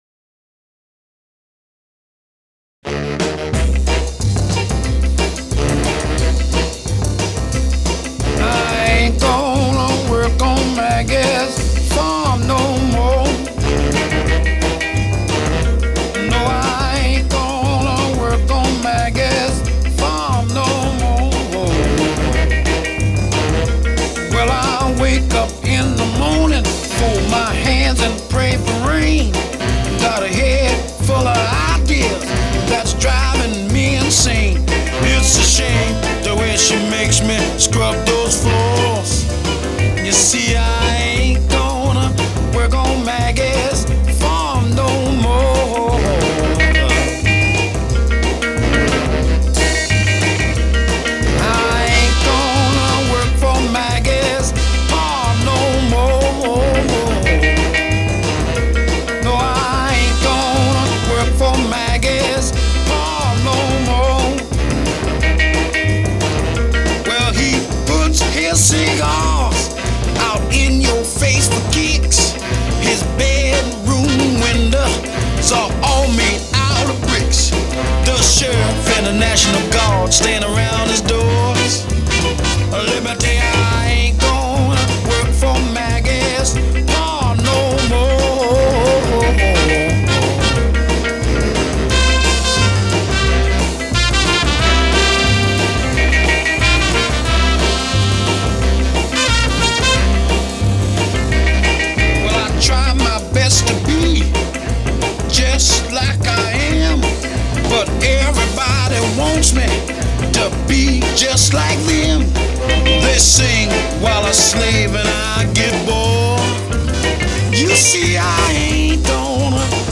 rhythm and blues